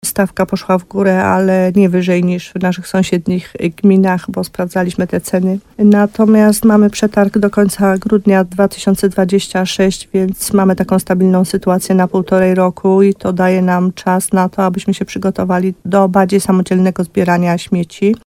– Nie był on już tak korzystny jak poprzedni – mówi wójt Małgorzata Gromala.